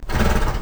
c_horsexxx_slct.wav